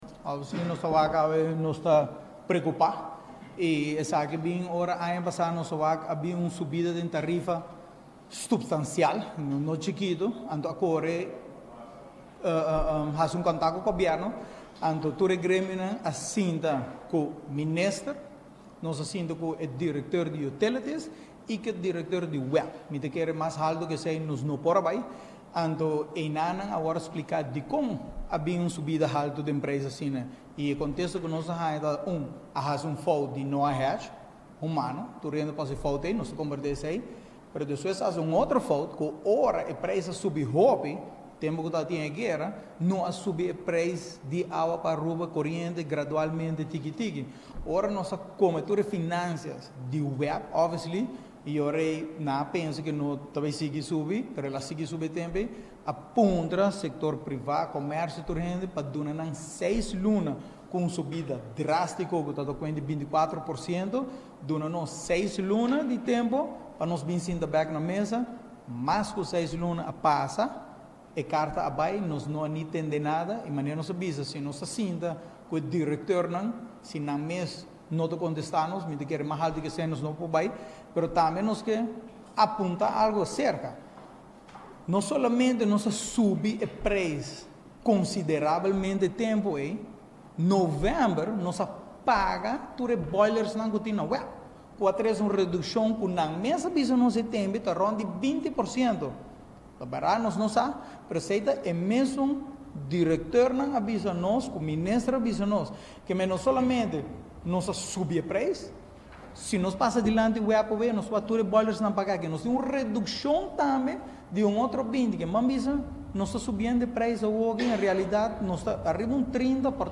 Na Aruba Trade & Industry Association (ATIA) a tuma luga un conferencia di prensa di grupo di gremio comercial,grupo sindical y grupo di pensionadonan. E punto cu tin riba mesa ta trata di e carta cu a keda manda for di prome di februari y no a haya un contesta te ainda di gobierno.